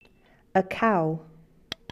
animal2 cow
animal2-cow.mp3